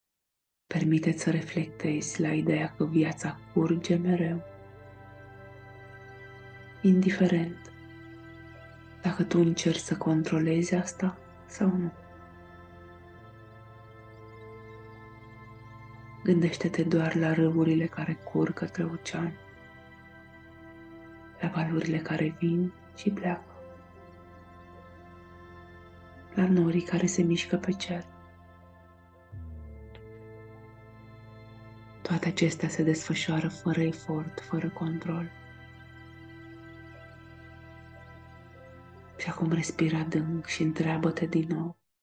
Meditatie audio Ghidata